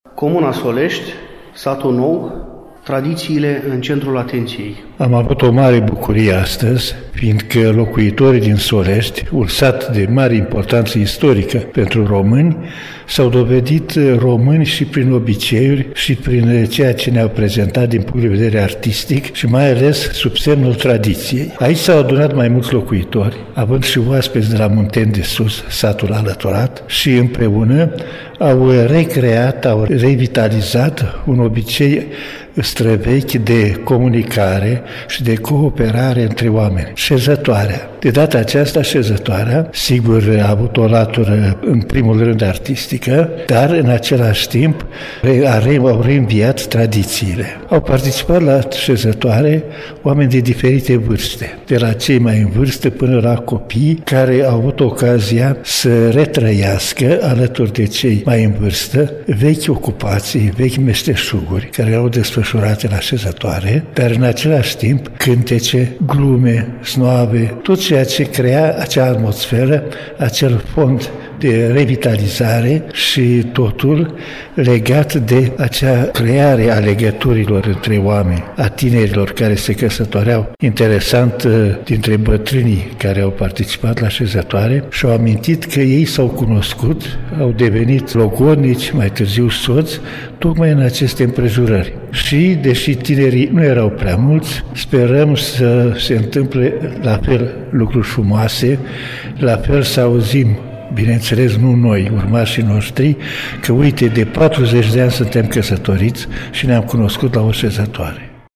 Concret, am asistat la o șezătoare precum era altădată prin satele moldave.